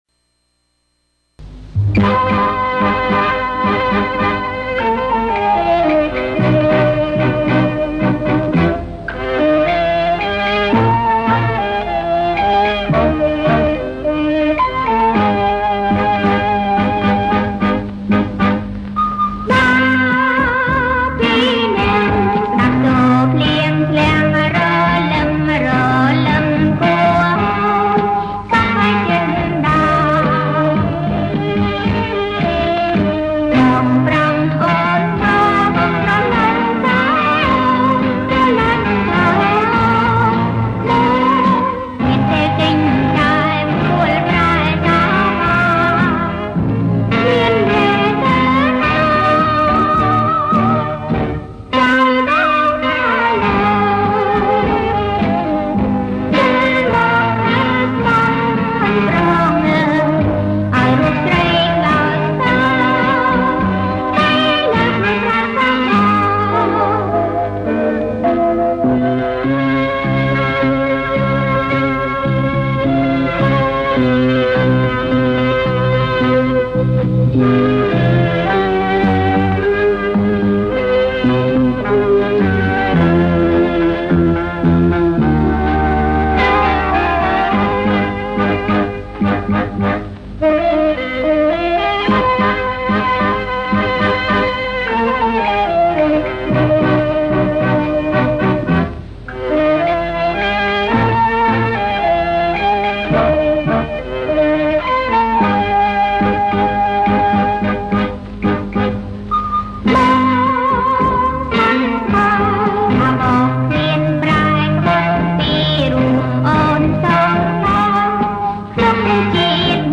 • ប្រគំជាចង្វាក់ Bolero